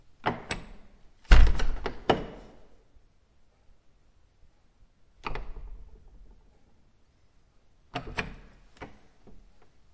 教堂门 " 教堂门（内部） Throwleigh sel
描述：重型木制教堂门，从里面打开和关闭。
Tag: 沉重 现场 记录